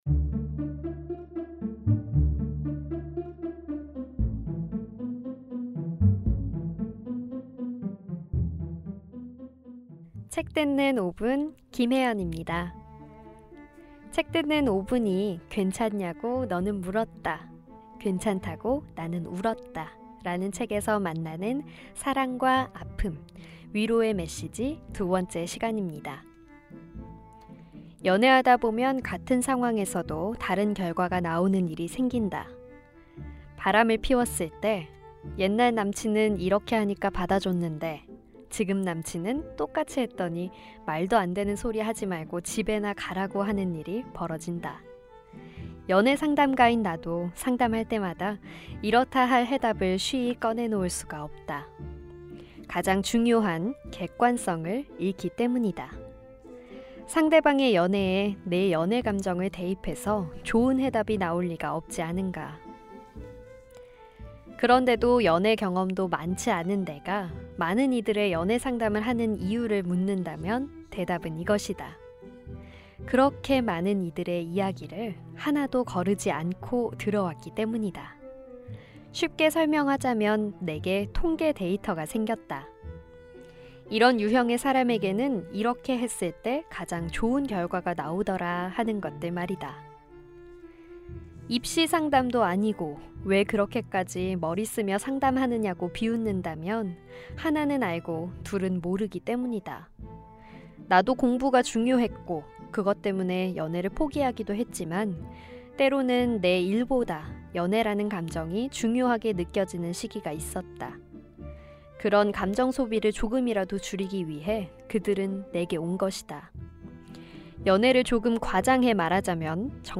북 큐레이터